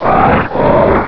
sovereignx/sound/direct_sound_samples/cries/camerupt.aif at master